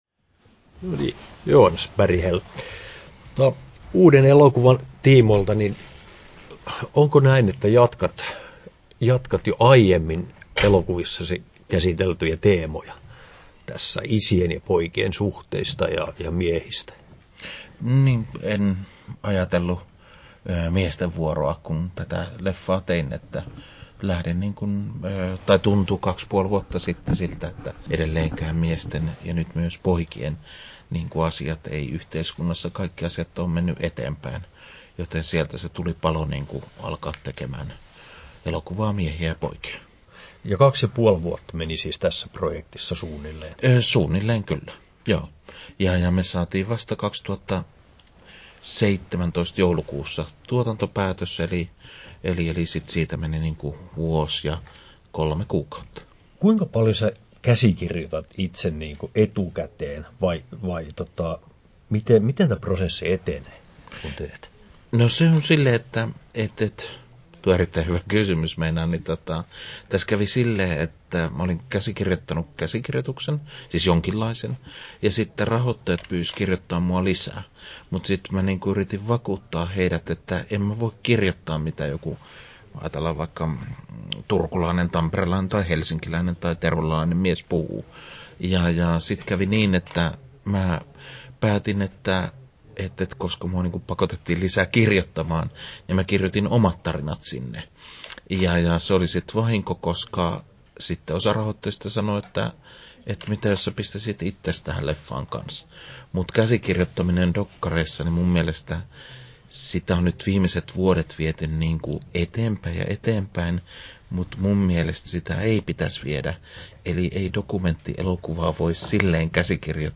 Haastattelut
Haastattelussa
10'07" Tallennettu: 21.03.2019, Turku Toimittaja